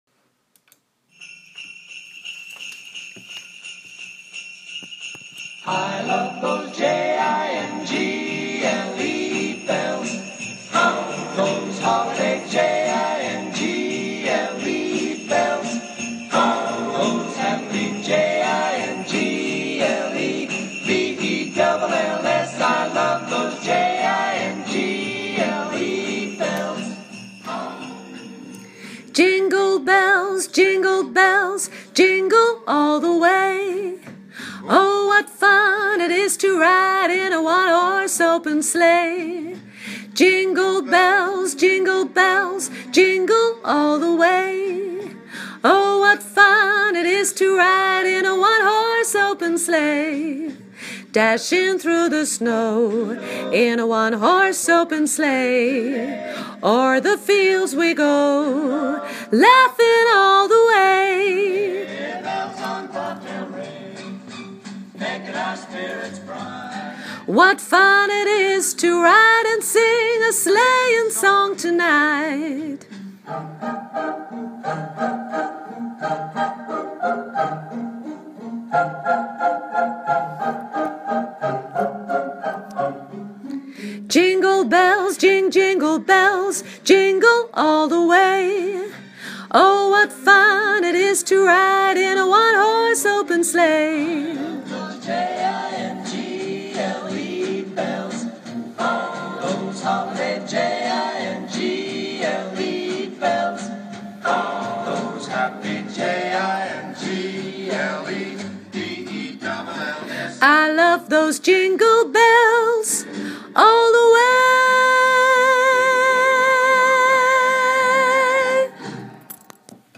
lead